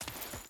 Footsteps / Dirt / Dirt Chain Walk 3.wav
Dirt Chain Walk 3.wav